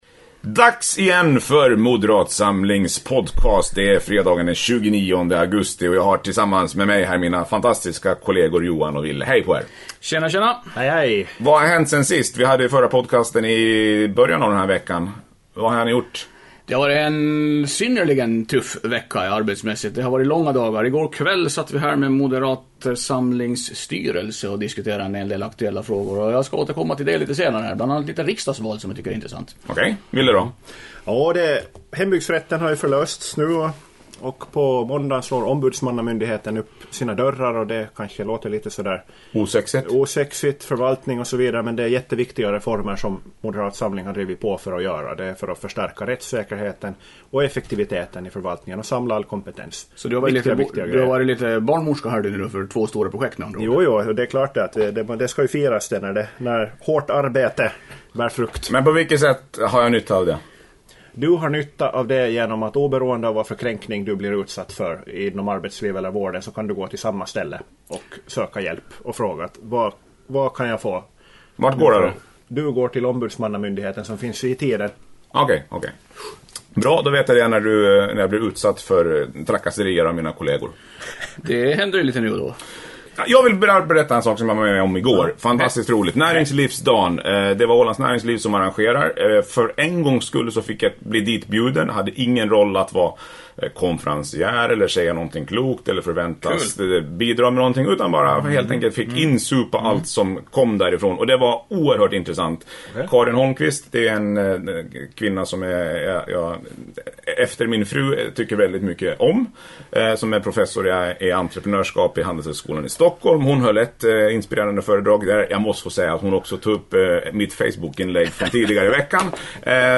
I dagens podcast pratar jag (tyvärr lite för mycket) tillsammans med mina moderata ministerkolleger Johan Ehn ochWille Valve kring kaffebordet om vad som hänt i veckan och kommer att hända nästa. Bland annat får du höra lite tankar om näringslivsdagen, riksdagsvalet, hembygdsrätten, kollektivtrafiken och evigt liv.